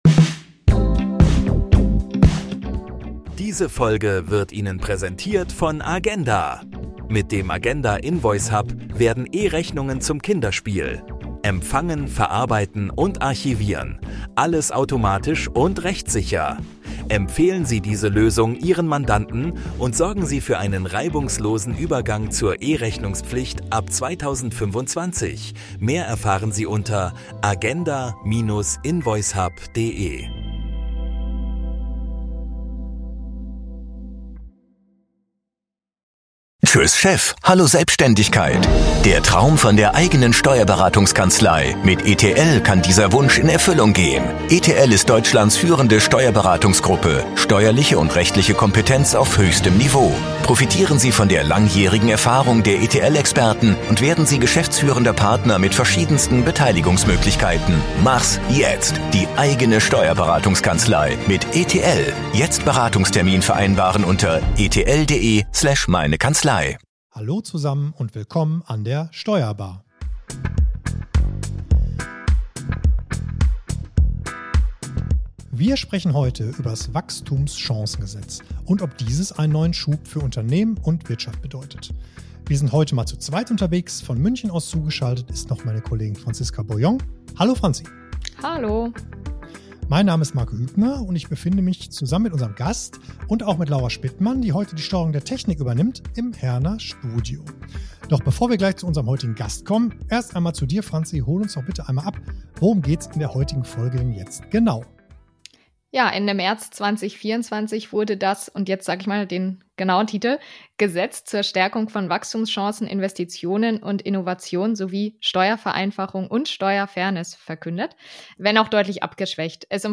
Experteninterviews rund um die Themen Wirtschaft, Steuern und Recht.